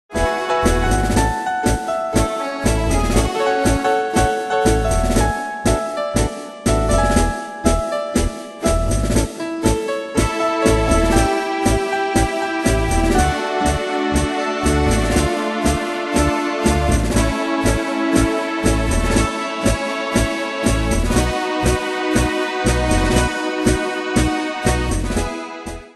Style: Retro Ane/Year: 1959 Tempo: 120 Durée/Time: 2.42
Danse/Dance: Tango Cat Id.
Pro Backing Tracks